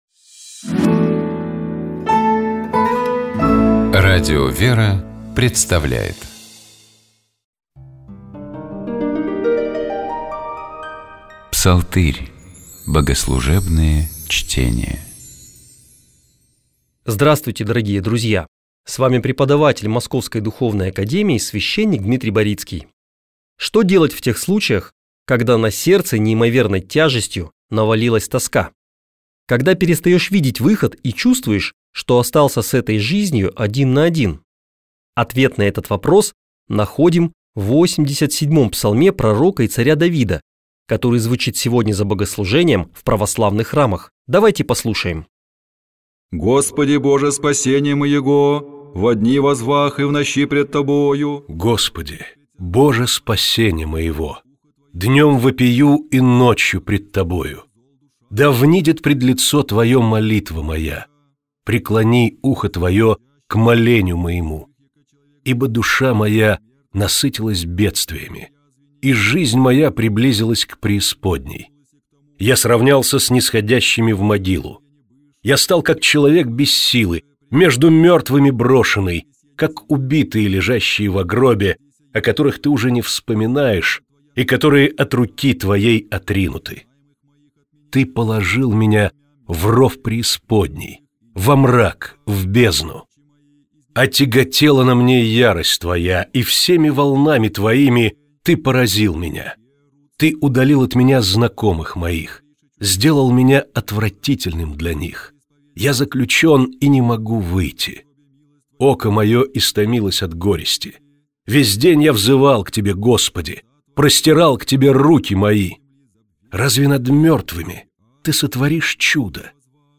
Псалом 87. Богослужебные чтения